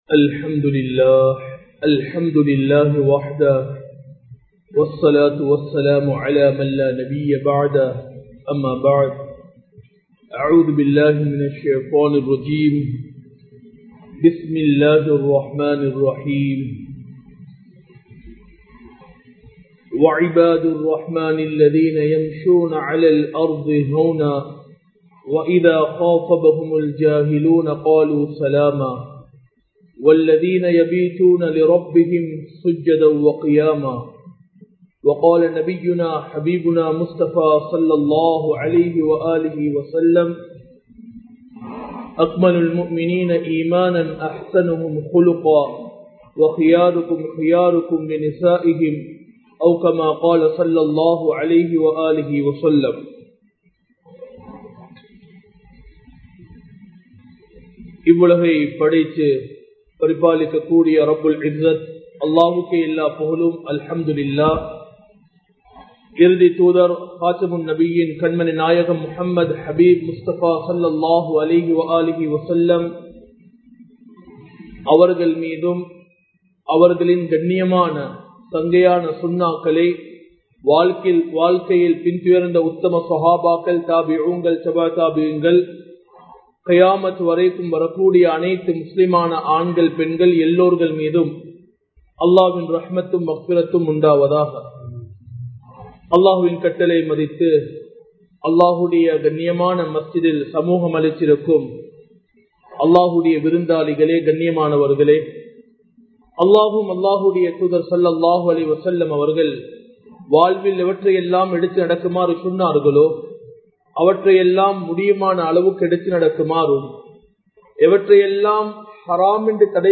ஹலாலான உழைப்பும் கஃபன் துணியும் | Audio Bayans | All Ceylon Muslim Youth Community | Addalaichenai
Majma Ul Khairah Jumua Masjith (Nimal Road)